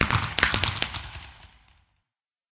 twinkle_far1.ogg